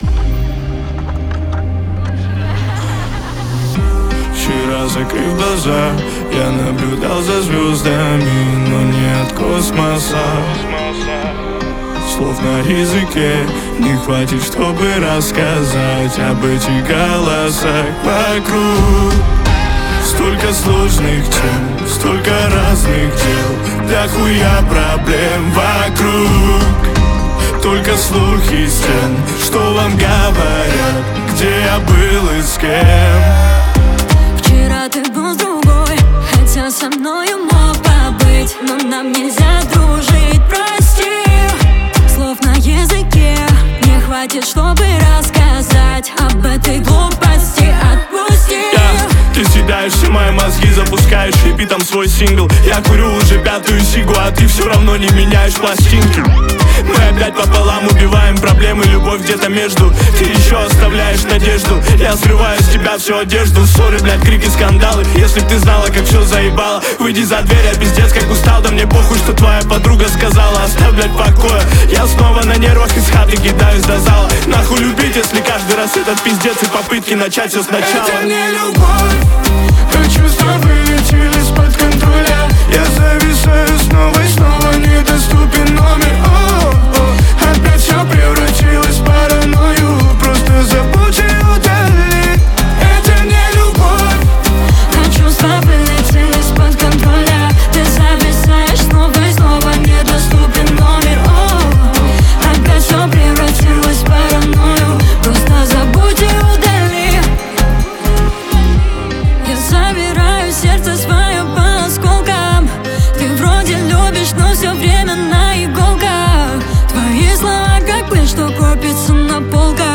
Клубные хиты